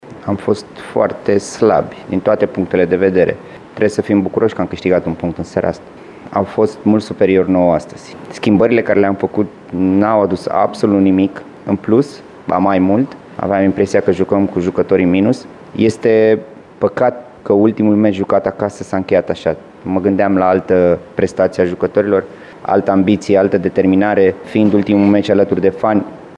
La declarațiile din finalul întâlnirii, ambii antrenori au exprimat aceeași idee – că UTA ar fi trebuit să ia toate punctele din această întâlnire.
Omologul său din Bănie, Laurențiu Reghecampf, și-a catalogat proprii jucători ca fiind ”foarte slabi”: